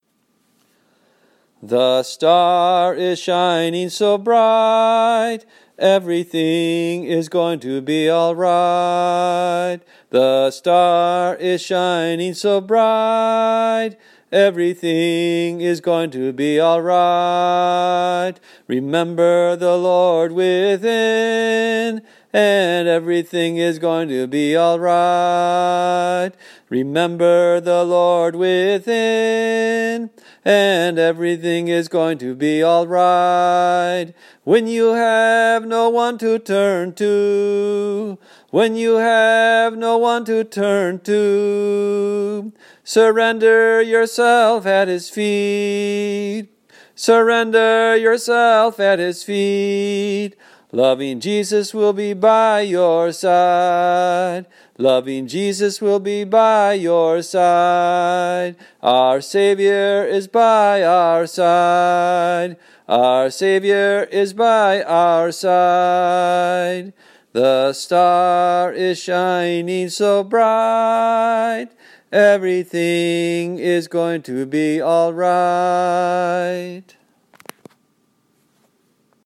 1. Devotional Songs
Major (Shankarabharanam / Bilawal)
8 Beat / Keherwa / Adi
Fast
5 Pancham / G
2 Pancham / D